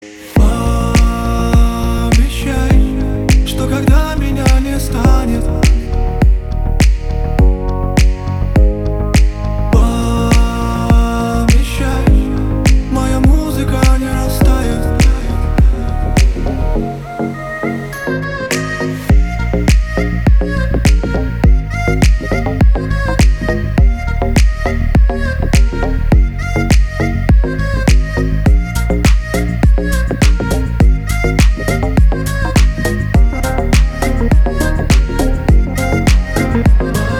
• Качество: 320, Stereo
мужской вокал
deep house
восточные мотивы